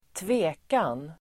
Uttal: [²tv'e:kan]